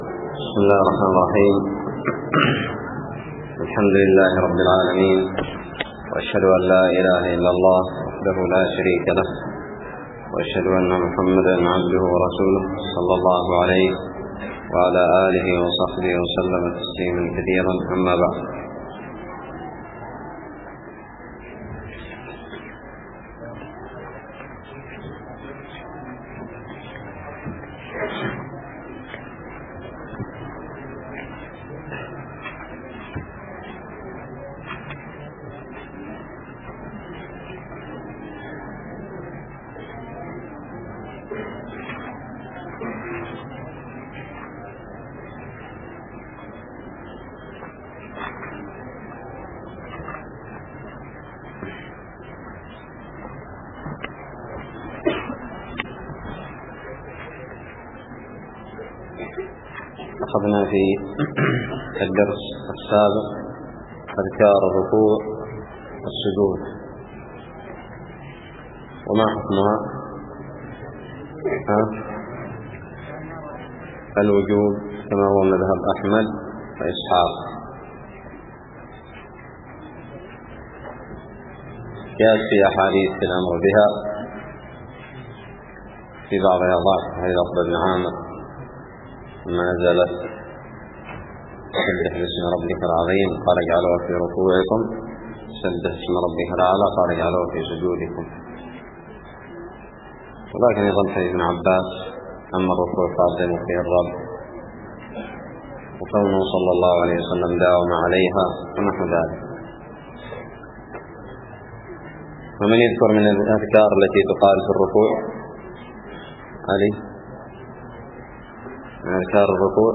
الدرس الخامس والسبعون من كتاب الصلاة من الدراري
ألقيت بدار الحديث السلفية للعلوم الشرعية بالضالع